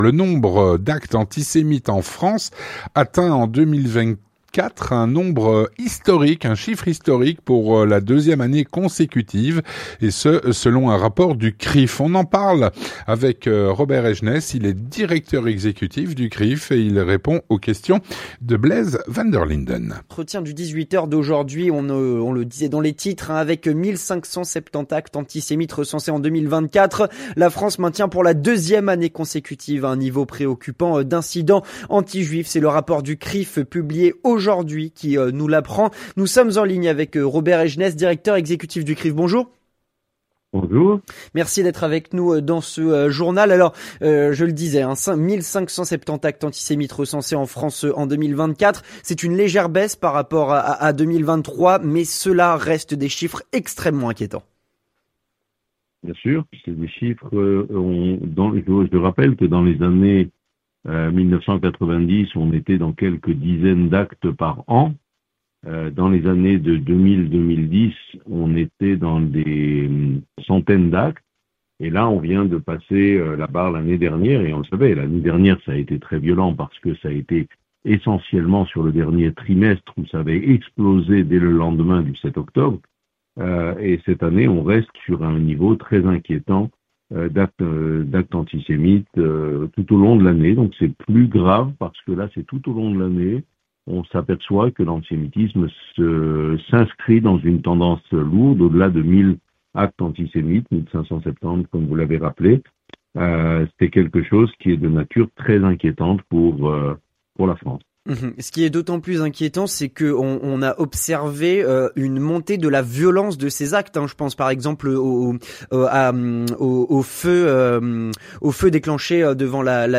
L'entretien du 18H - Le nombre d'actes antisémites en France atteint en 2024 un nombre "historique" pour "la deuxième année consécutive".